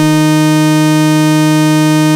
OSCAR 13 D#4.wav